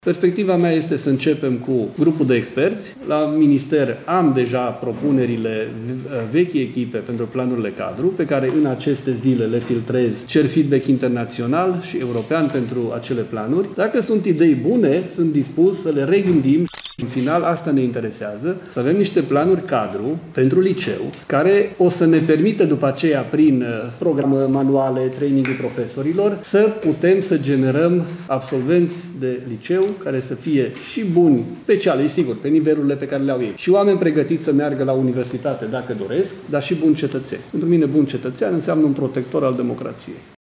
Ministrul Daniel David a explicat procedura și ce așteptări are după aplicarea noilor planuri-cadru